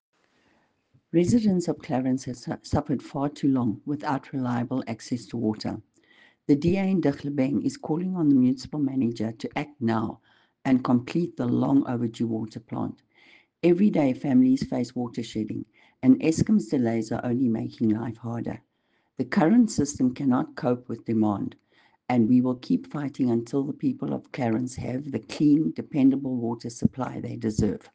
Afrikaans soundbites by Cllr Irene Rügheimer and